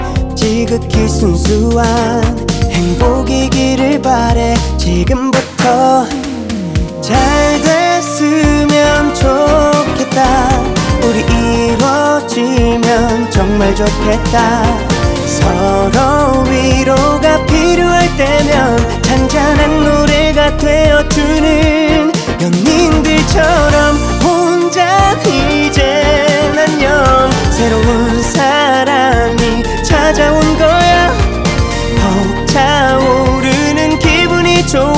2025-07-24 Жанр: Танцевальные Длительность